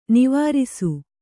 ♪ nivārisu